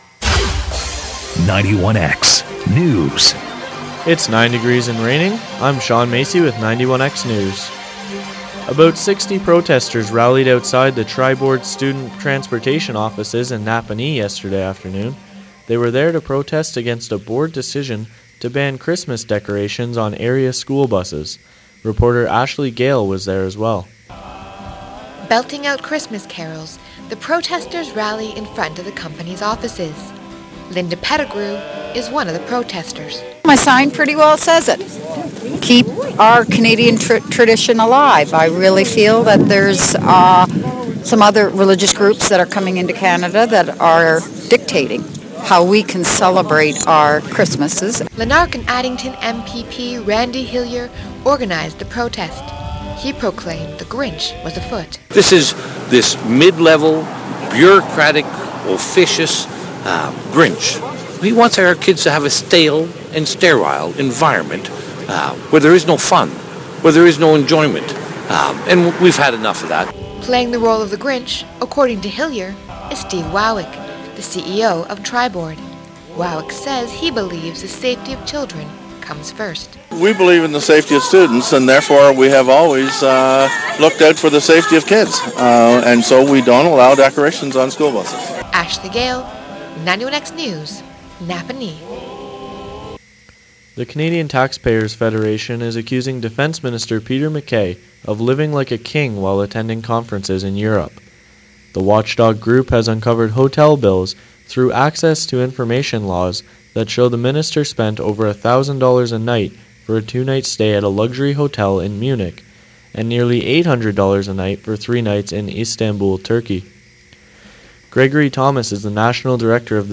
The Belleville Bulls look to get out of a rut after losing to the Frontenacs. For these stories, sports and more, listen to the 1 p.m newscast.